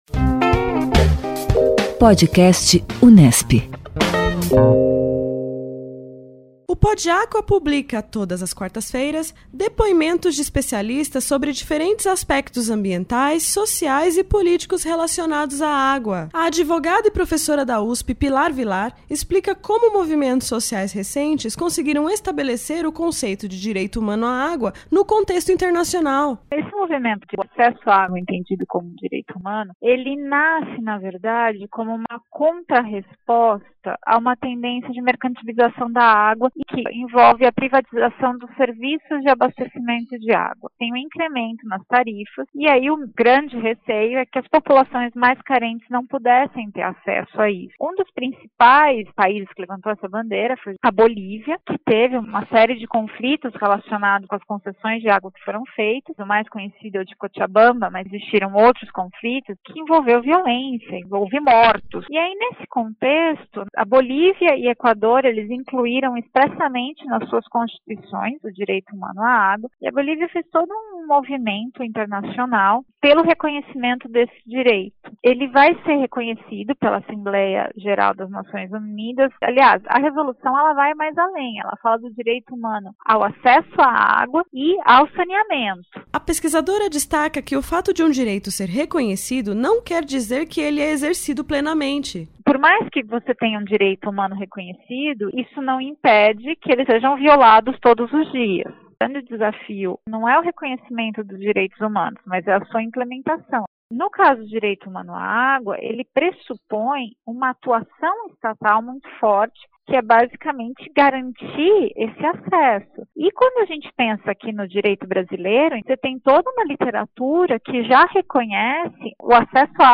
O PodAcqua traz trechos de entrevistas com especialistas da Unesp e de outras instituições, nas mais diferentes áreas do conhecimento, com atenção especialmente voltada à gestão responsável dos recursos hídricos.